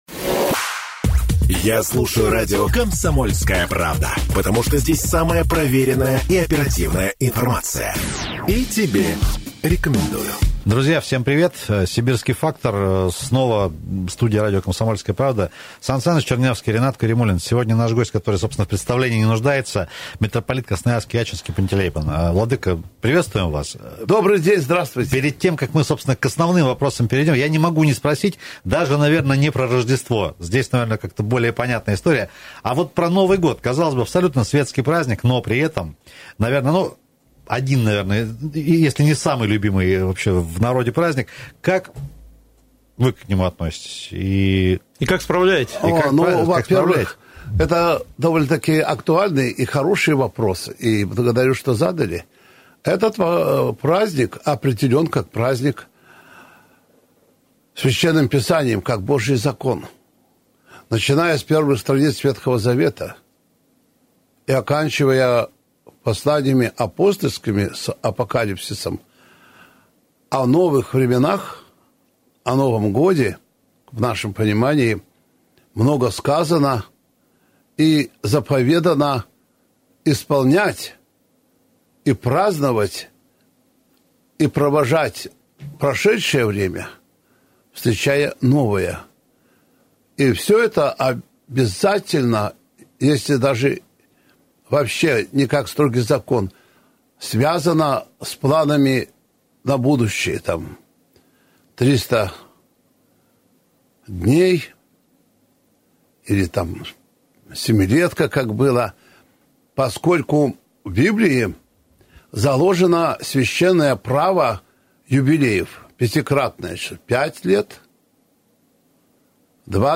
Интервью Митрополита Красноярского и Ачинского Пантелеимона в программе “Сибирский фактор” на радио “КП — Красноярск”, выходящей при поддержке “Сибирского клуба”.